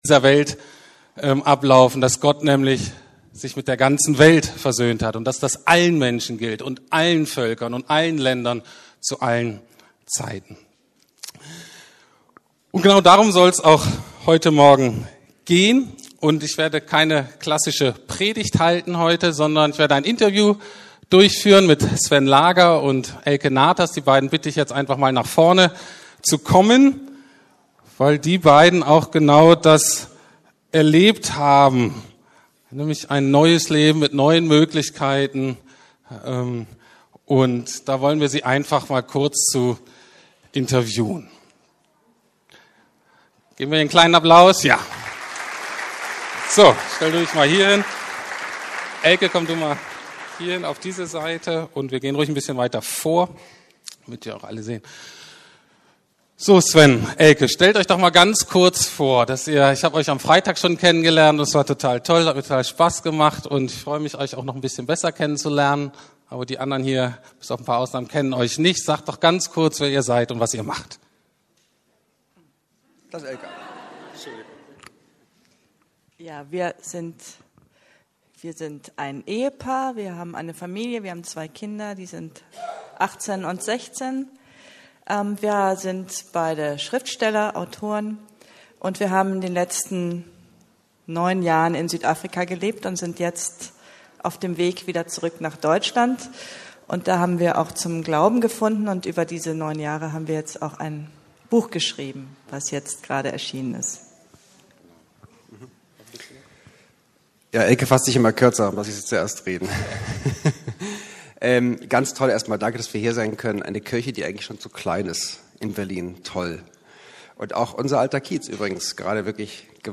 Taufgottesdienst